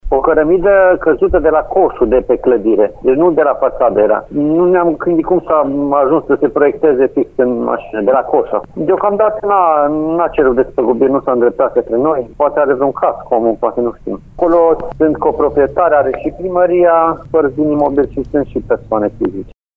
Proprietarul nu a făcut deocamdată o solicitare de despăgubire, spune primarul interimar al Lugojului, Bogdan Blidariu.